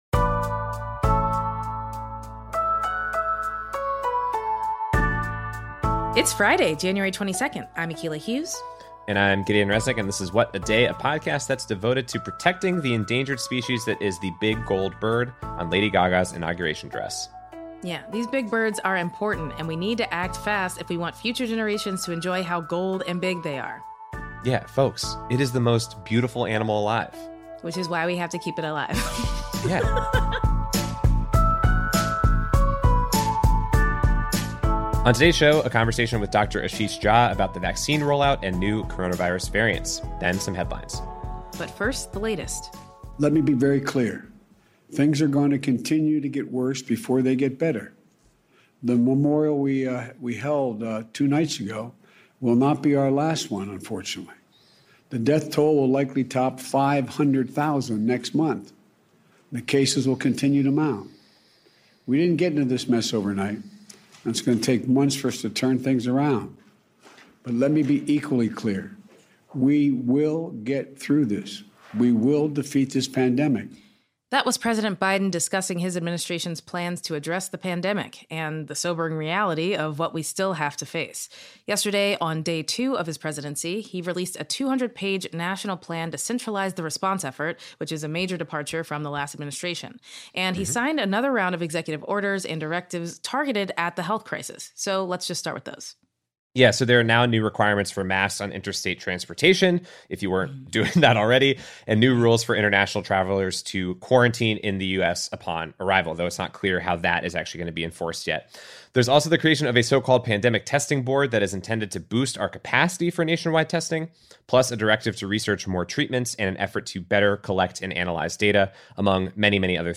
We spoke with Dr. Ashish Jha, Dean of Brown University’s School of Public Health about that plan, whether it’s fast enough, the threat of new variants, and more.
And we’re joined by actor and comedian Alice Wetterlund for headlines: rumors of a forbidden romance between a 30 Rock Star and a MyPillow guy, new rooster laws in France, and Instacart fires all its unionized employees.